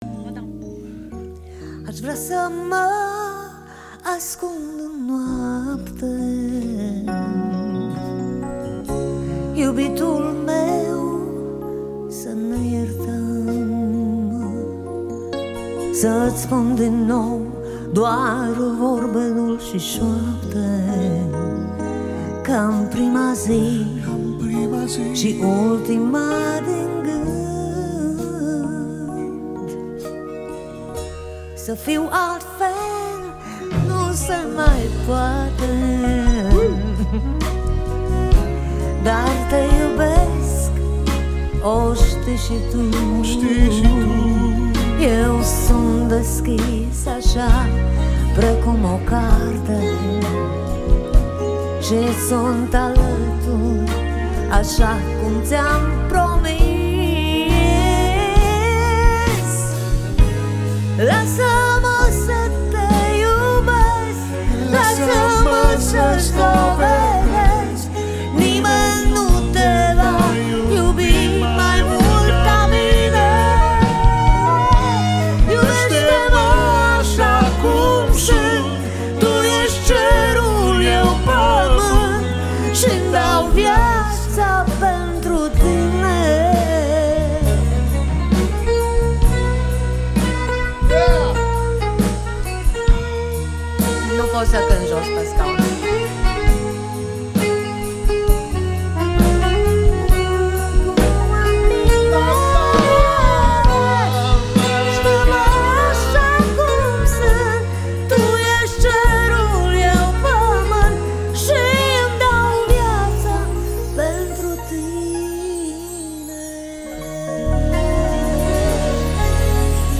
Pian
Chitara
Percutie